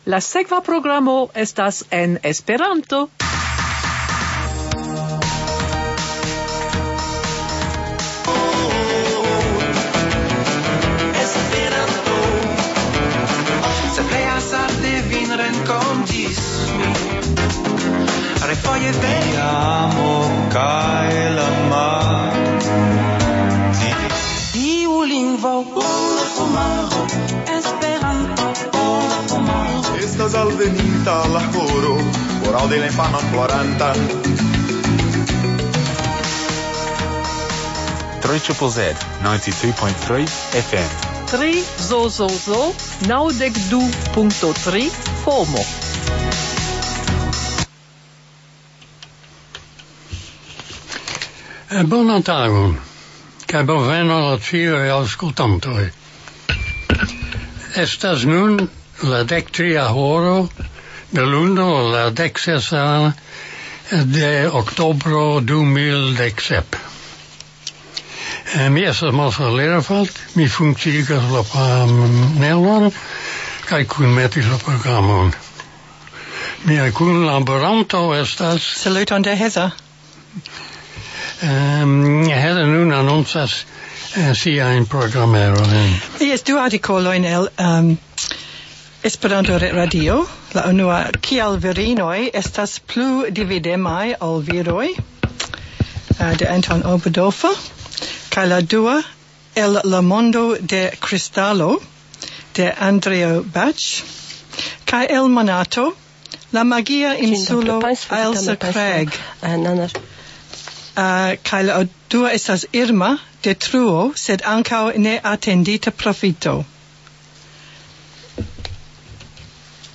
Kanto